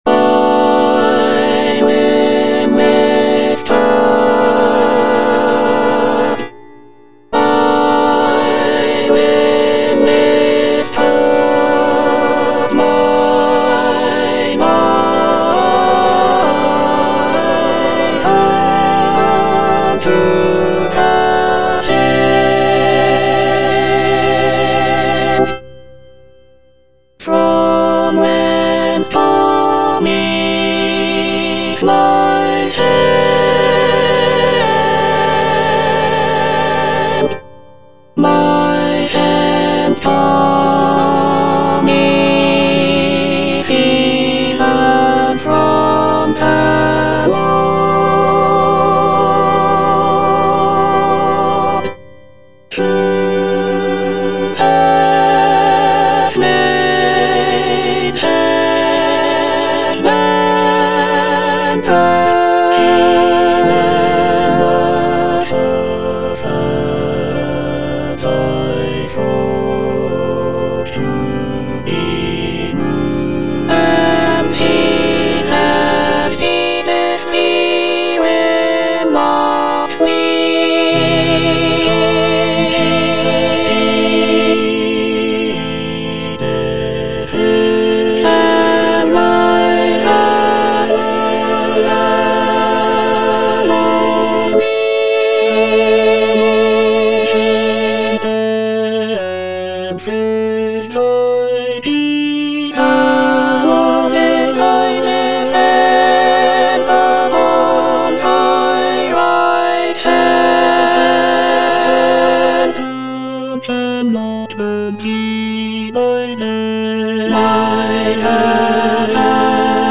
Alto II (Emphasised voice and other voices)